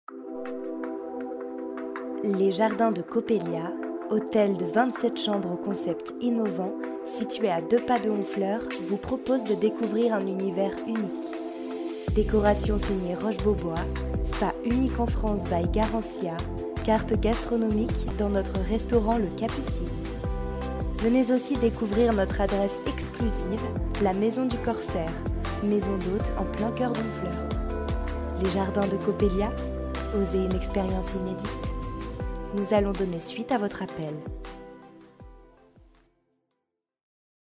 Démo voix off
15 - 40 ans - Mezzo-soprano